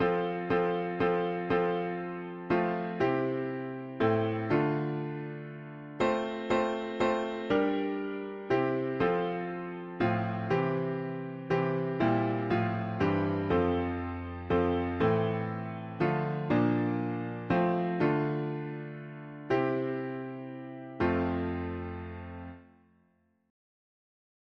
By faith made strong, the rafters w… english secular 4part
Key: F major